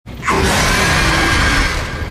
Catnap Jumpscare Sound Effect Free Download
Catnap Jumpscare